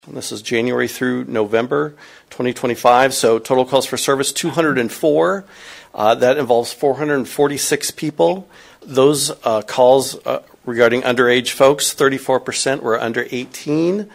MPACT Quarterly Report at Marshalltown City Council Meeting | News-Talk 1230 KFJB